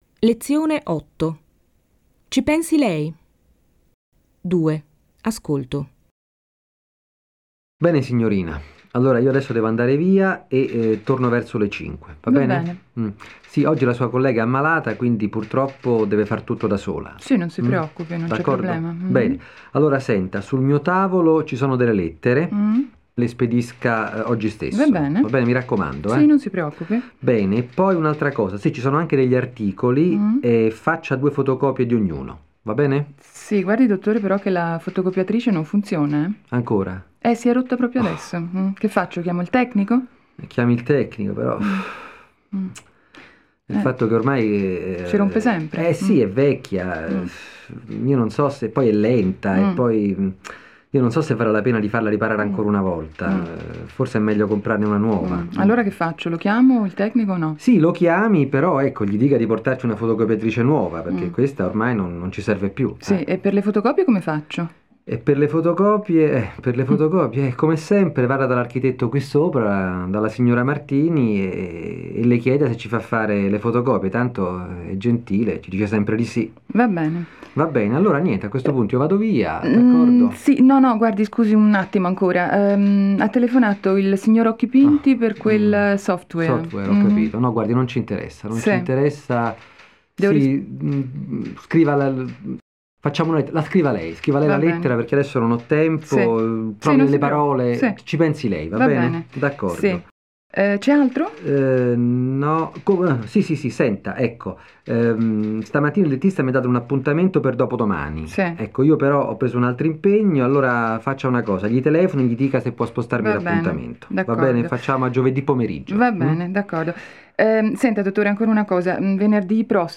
Lei ascolta un dialogo.
Sie hören einen Dialog.